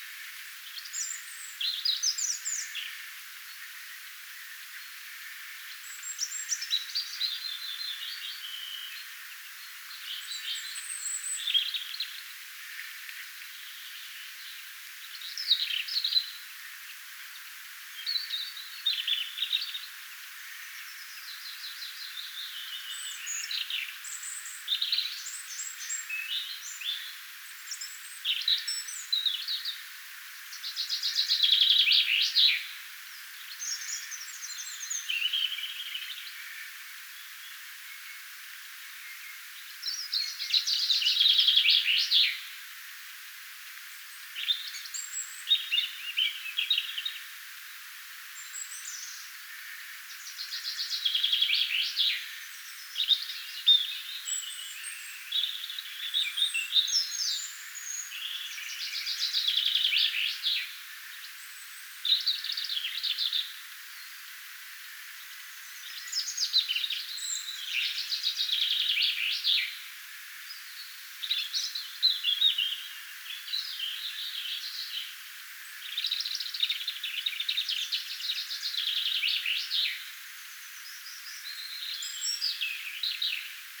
punarintojen laulutyyli muuttuu
ehkapa_punarintojen_laulutyyli_muuttuu_sen_jalkeen_kun_haudonta_alkaa.mp3